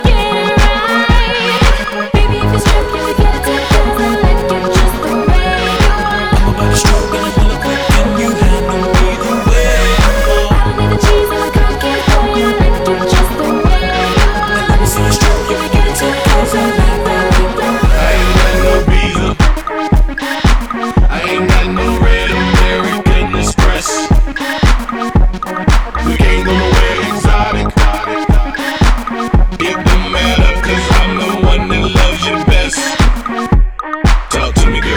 Жанр: Рок / R&B / Соул